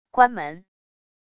关门wav音频